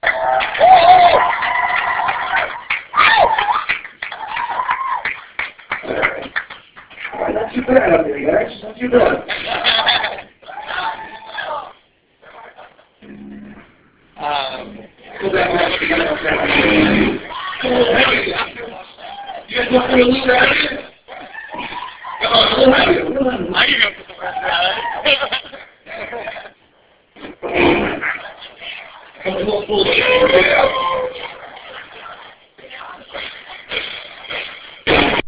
crowd – Hofstra Drama 20 – Sound for the Theatre
Sounds In Clip: The automatic doors opening; People talking and laughing; A metal object banging against another solid object; Chairs being scraped across the floor; Footsteps as some people are walking past
Location: Student Center